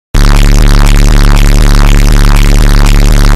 Bass boosted sound effect